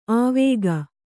♪ āvēga